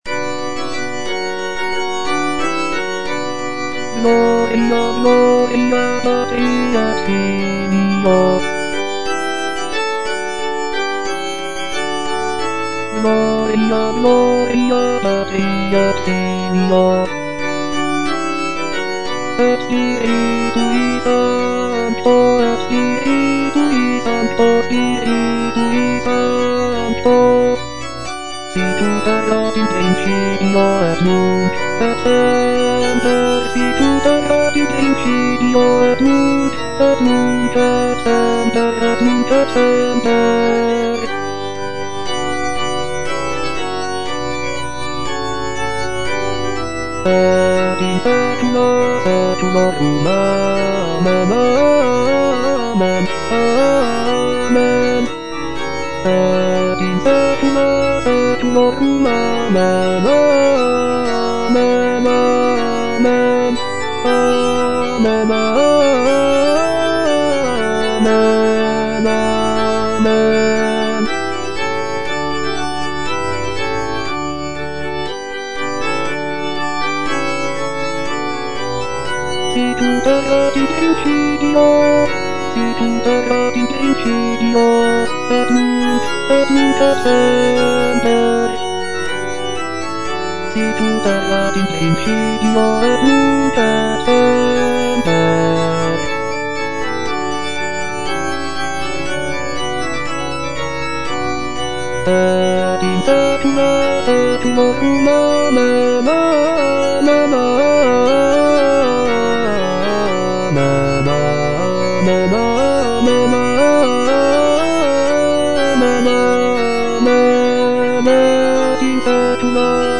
M.R. DE LALANDE - CONFITEBOR TIBI DOMINE Gloria Patri (baritone) - Bass (Voice with metronome) Ads stop: auto-stop